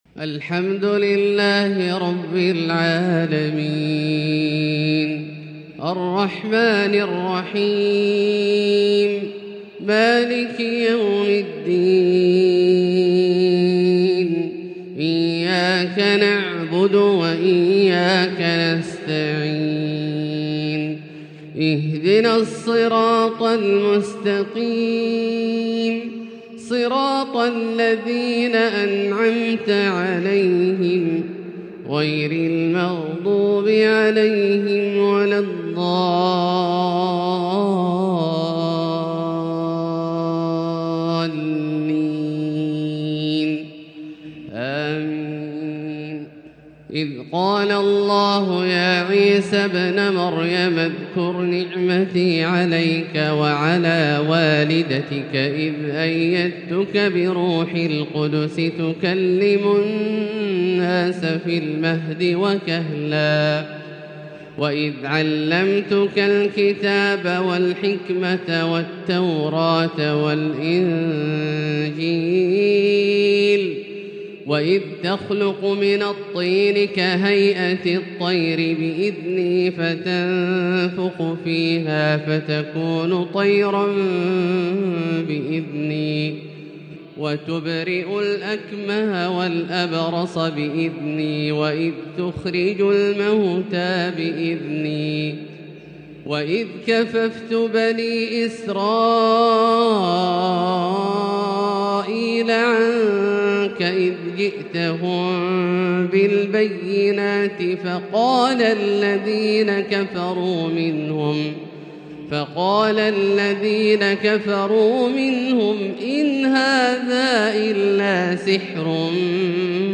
فجر الخميس 5-2-1444هـ آخر سورة المائدة | Fajr prayer from Surat Al-Maaida 1-9-2022 > 1444 🕋 > الفروض - تلاوات الحرمين